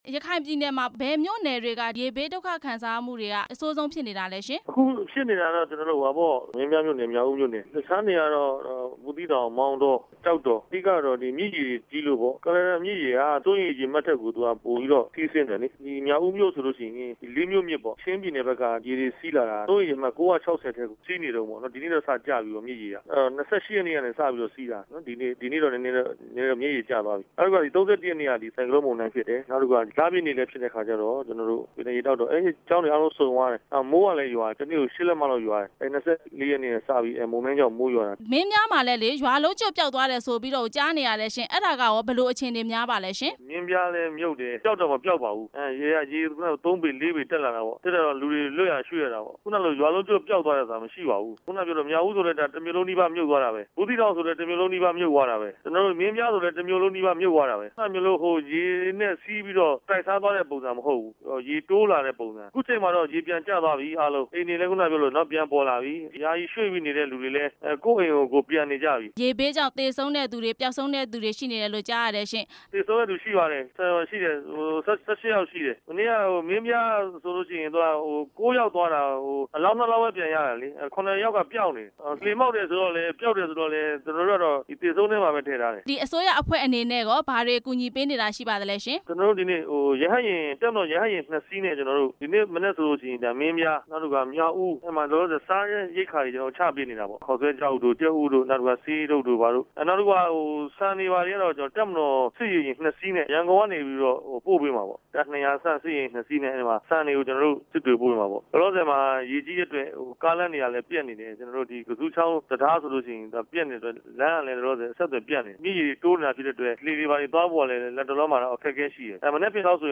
ရခိုင်ပြည်ရေဘေး ကူညီပေးနေ တဲ့အကြောင်း မေးမြန်းချက်